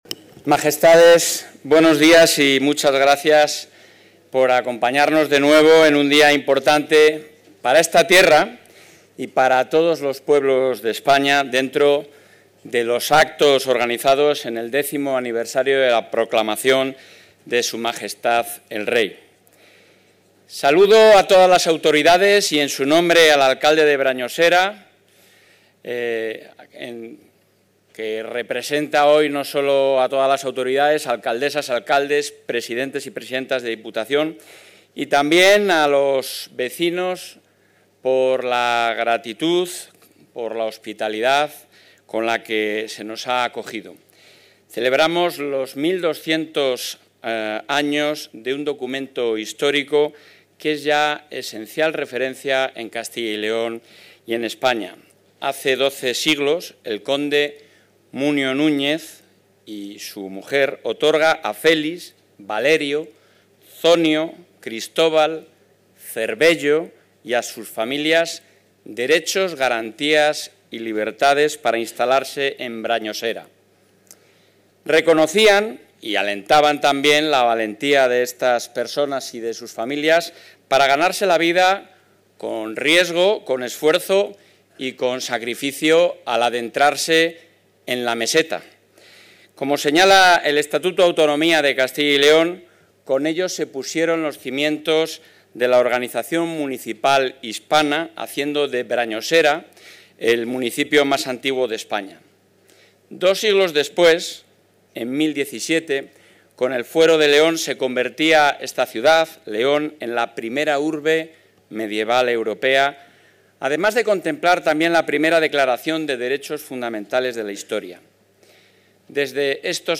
Intervención del presidente de la Junta.
El presidente de la Junta de Castilla y León, Alfonso Fernández Mañueco, ha asistido al acto conmemorativo del 1.200 aniversario del Fuero de Brañosera, presidido por Sus Majestades los Reyes de España, y organizado por el Gobierno autonómico en colaboración con la Diputación de Palencia y el Ayuntamiento de Brañosera, en el que se ha rendido homenaje al municipio más antiguo de España y al origen del municipalismo.